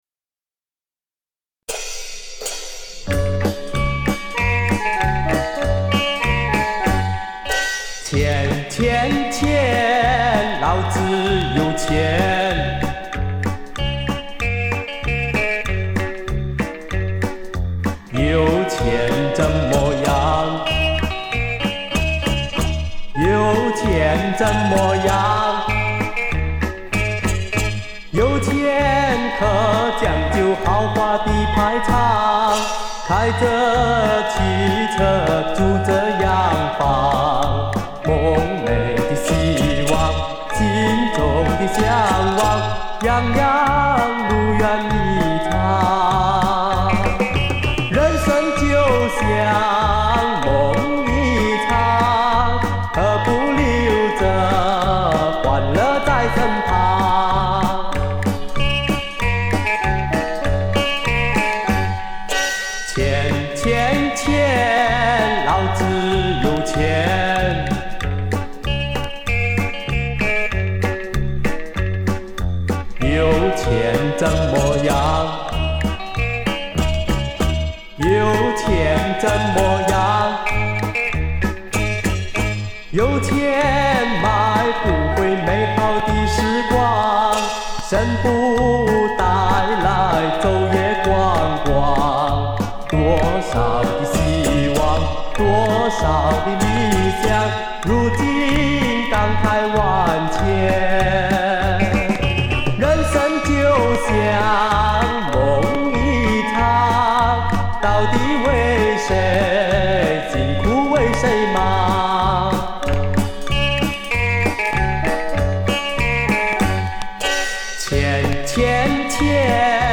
采样格式：LP转APE
采样硬件:松下SL-J85R唱机+铁三角MM唱头+TERRATEC外置USB声卡唱放